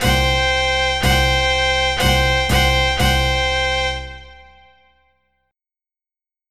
/ cdmania.iso / music / r_bmid / cntrypup.mid ( .mp3 ) < prev next > MIDI Music File | 1996-04-15 | 854b | 2 channels | 44,100 sample rate | 6 seconds Type General MIDI